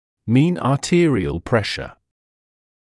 [miːn ɑː’tɪərɪəl ‘preʃə][миːн аː’тиэриэл ‘прэшэ]среднее артериальное давление